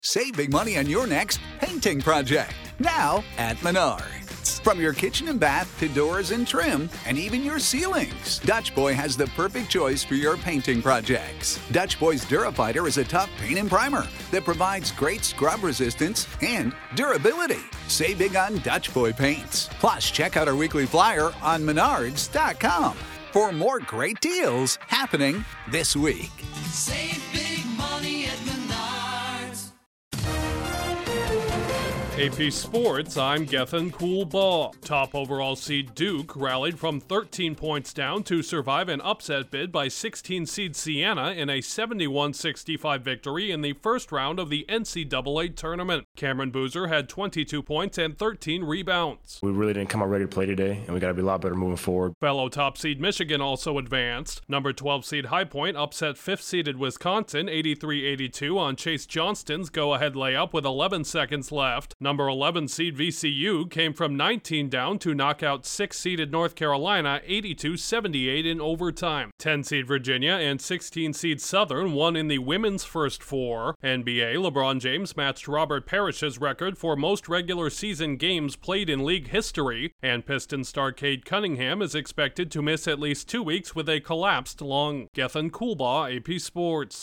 Sports News from the Associated Press / AP Sports Digest